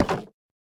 Minecraft Version Minecraft Version 1.21.5 Latest Release | Latest Snapshot 1.21.5 / assets / minecraft / sounds / block / nether_wood_fence / toggle4.ogg Compare With Compare With Latest Release | Latest Snapshot